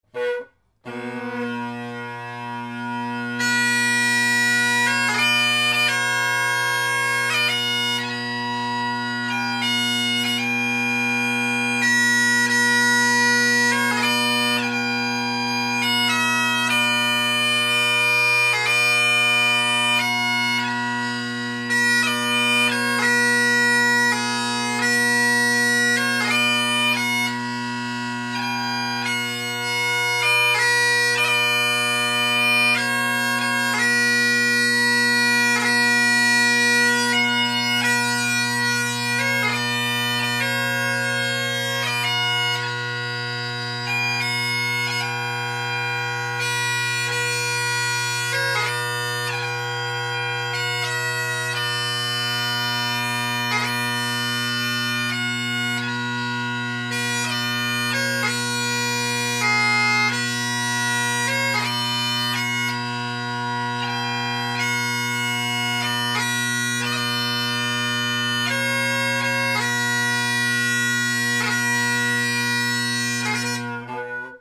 Great Highland Bagpipe Solo
Then swap the Canning tenors in:
Deer Forest – Canning tenors swapped in
The mic is about chanter level but behind and to my left, tenor side.
deer_forest-canning.mp3